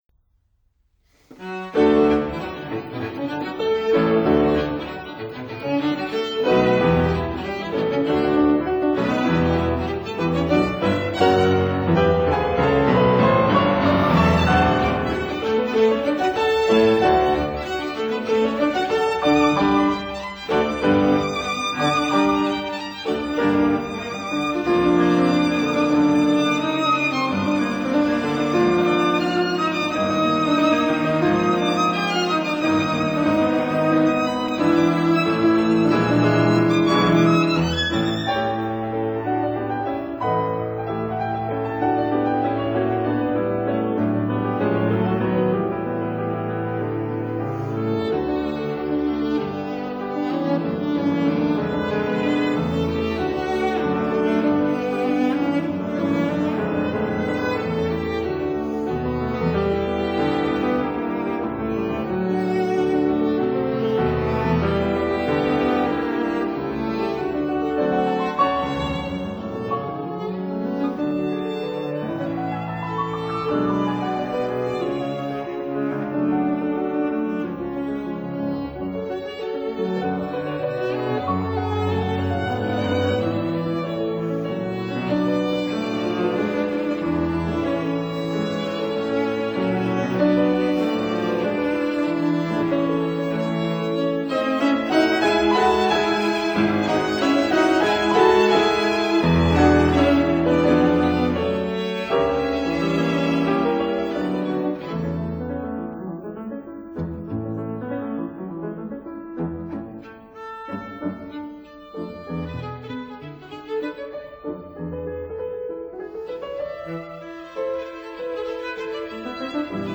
violin
cello